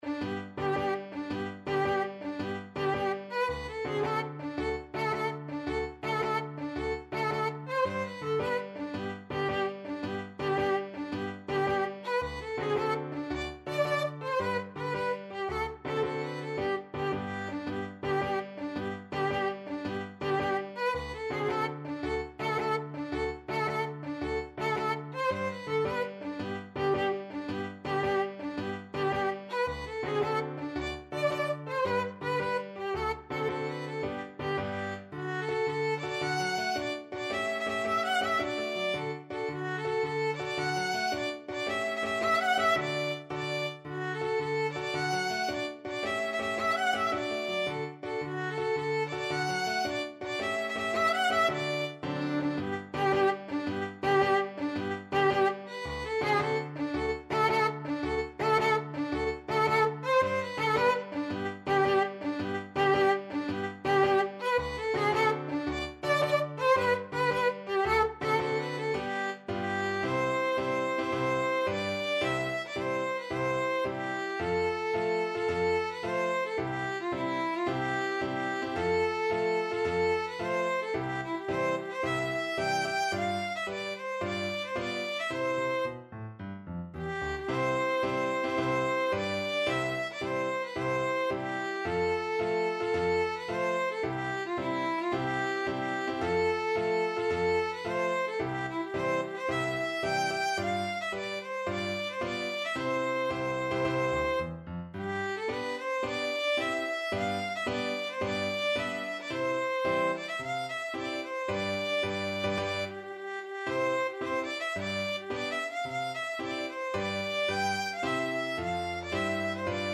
Violin
D5-G6
G major (Sounding Pitch) (View more G major Music for Violin )
6/8 (View more 6/8 Music)
piefke_koniggratzer_marsch_VLN.mp3